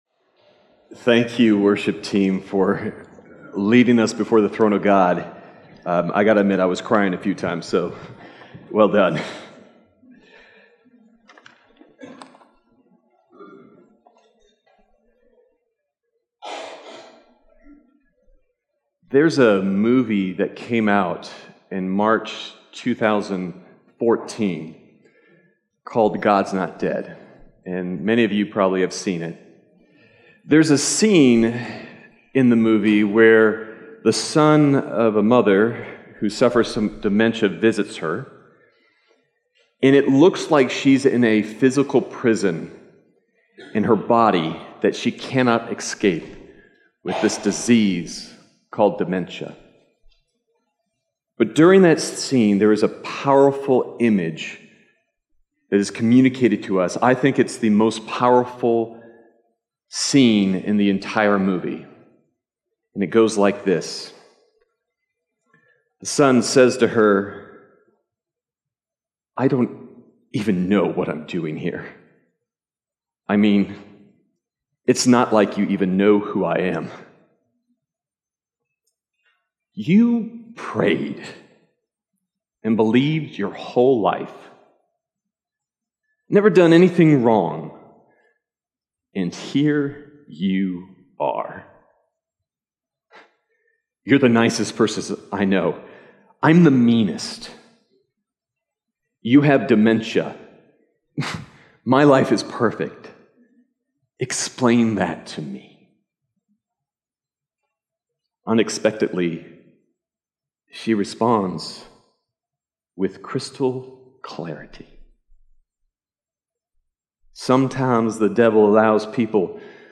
Sermon Detail
March_10th_Sermon_Audio.mp3